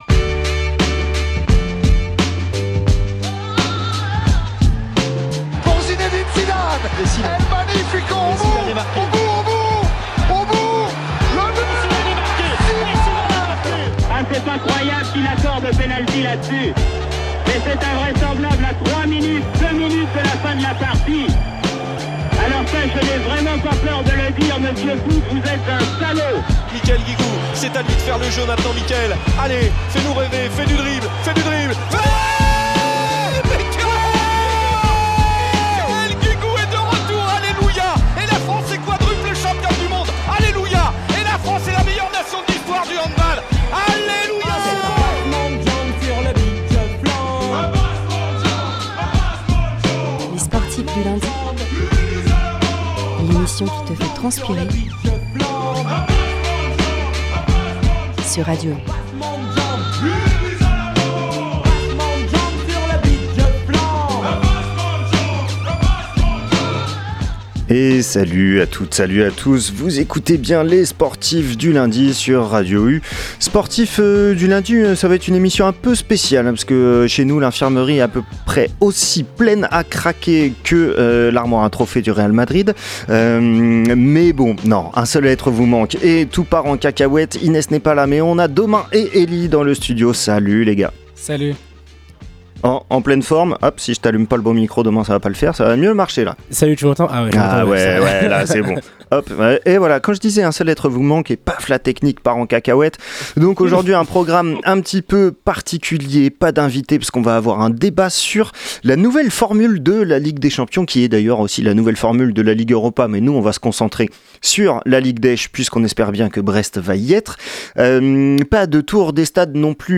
Débats, résumés de matches, chroniques, quizz et interviews axées sur le sport local : voilà…